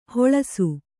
♪ hoḷasu